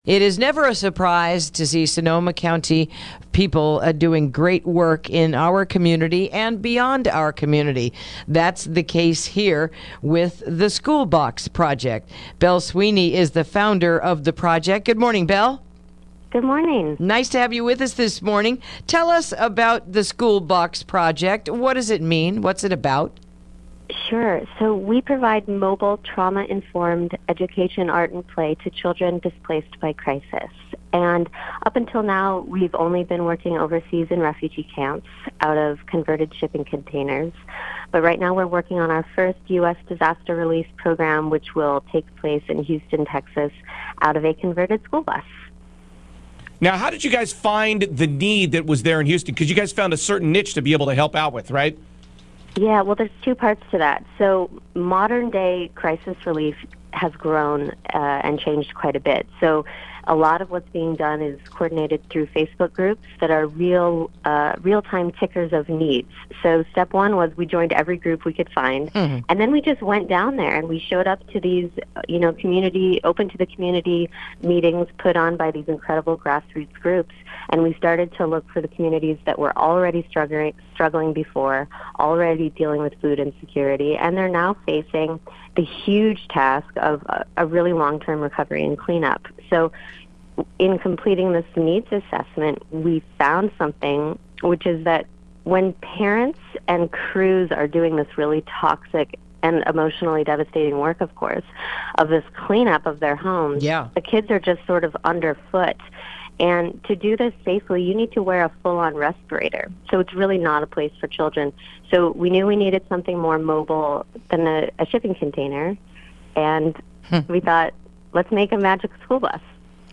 Interview: Sebastopol Nonprofit Helping in Houston